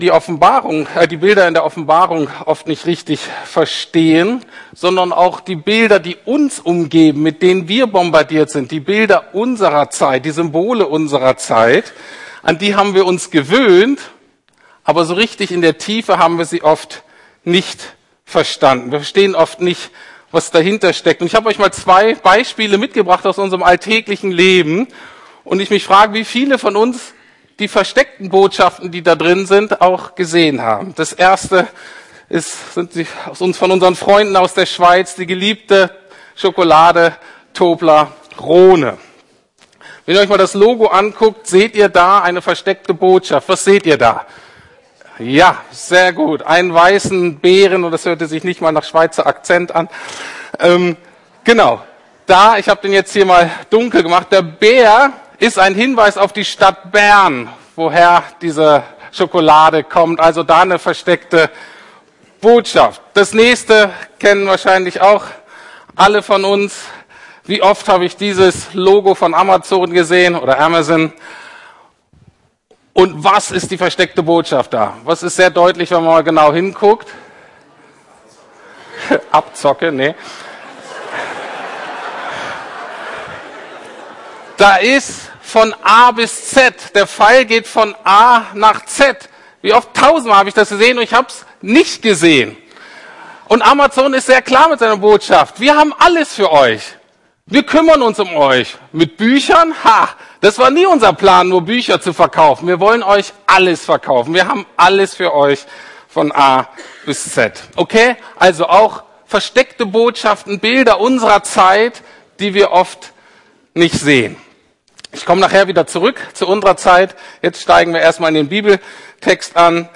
Das Buch, das glücklich macht (Teil 4) Zwei Tiere und eine Hure ~ Predigten der LUKAS GEMEINDE Podcast